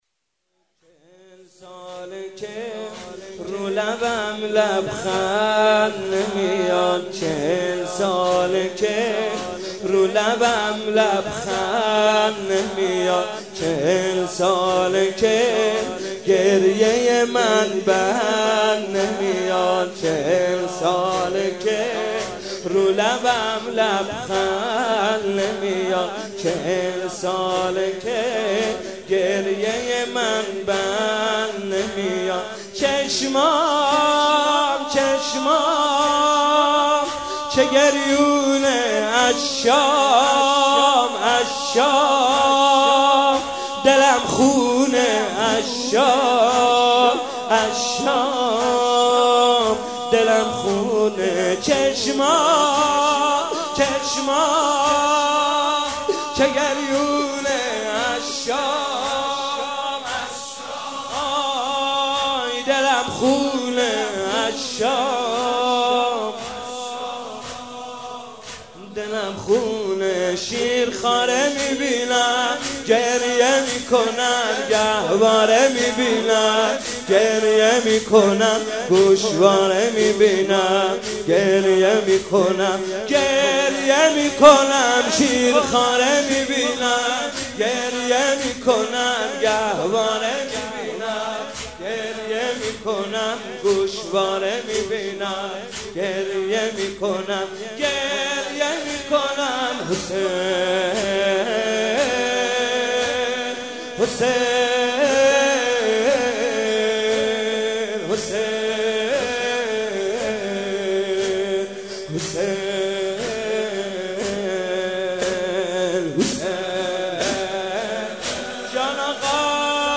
مناسبت : شب چهارم رمضان
قالب : زمینه